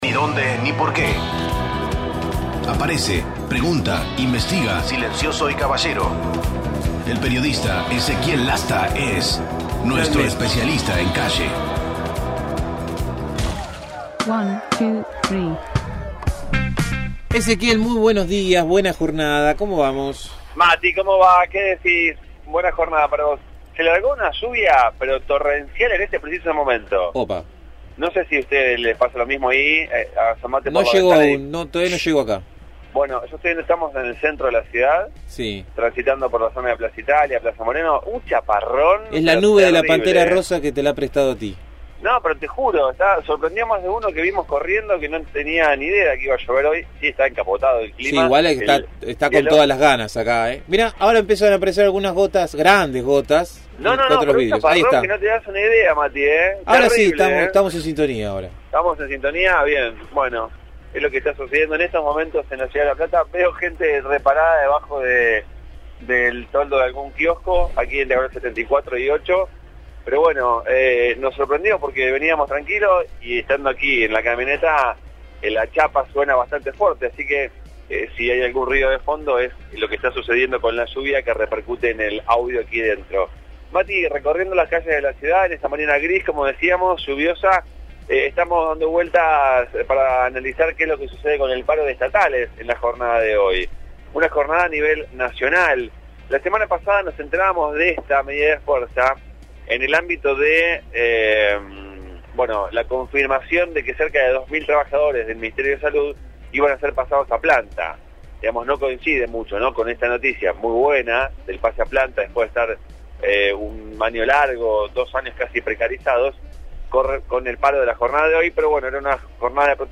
MÓVIL/ Paro de personal auxiliar nucleado en ATE